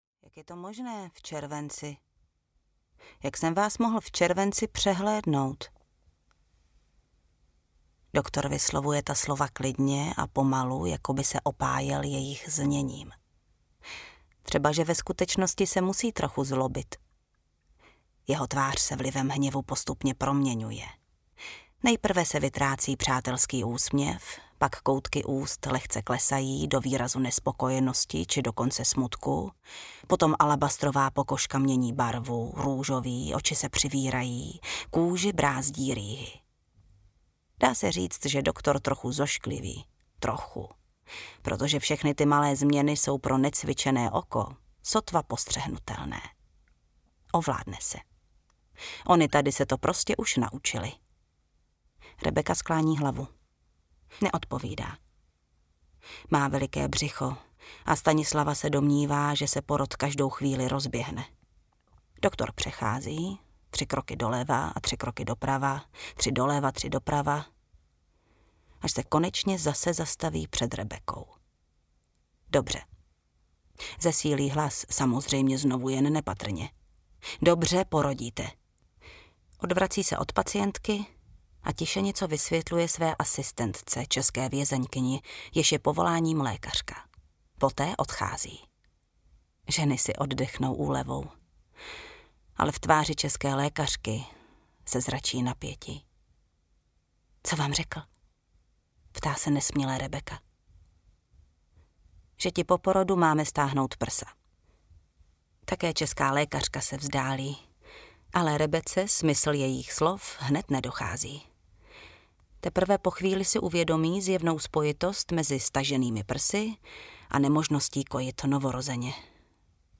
Porodní sestra z Osvětimi audiokniha
Ukázka z knihy
• InterpretJana Stryková